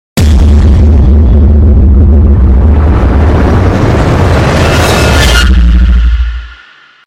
97 a countdown to something sound effects free download